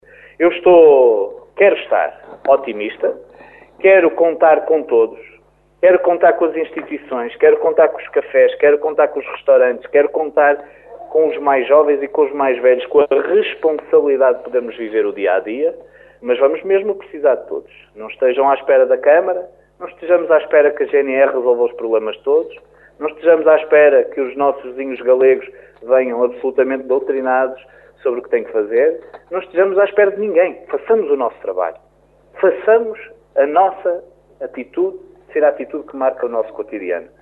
Apesar de todos os receios Miguel Alves diz estar otimista e apela ao bom senso de todfos para que tudo corra bem e não seja preciso dar passos para trás.